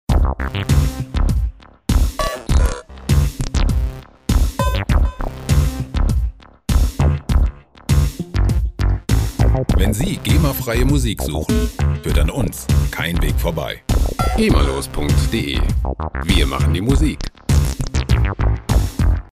• Electro Funk
Computermusik für die nächste Elektronik-Messe 19,00 EUR